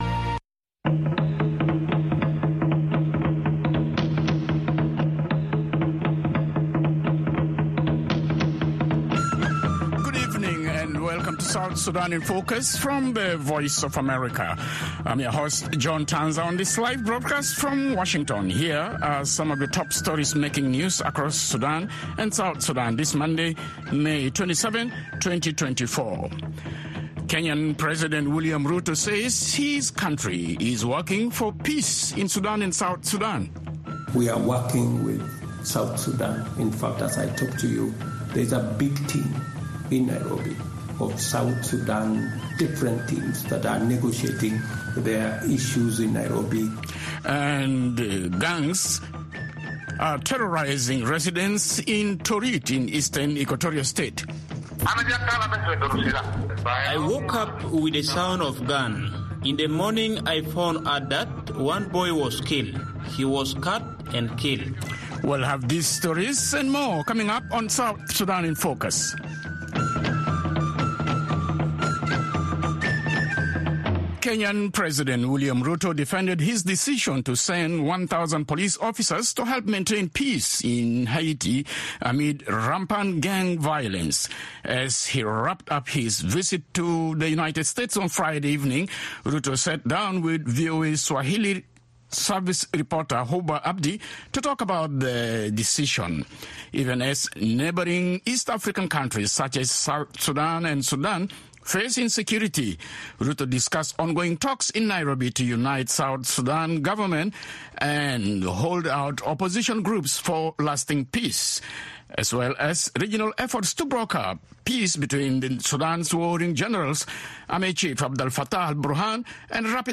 and a network of reporters around South Sudan and in Washington.